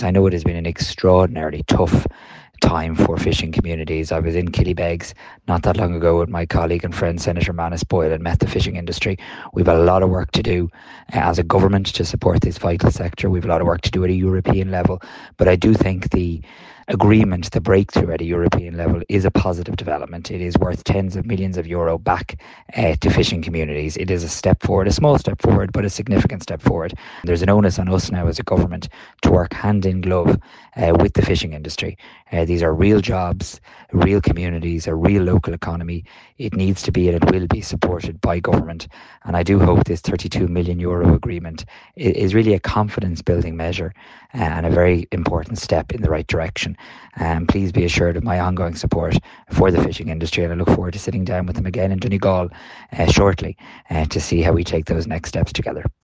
Meanwhile, in a voicenote sent to Highland Radio, Simon Harris recounted recent meetings in Killybegs, which he says left him in no doubt as to the importance of this decision………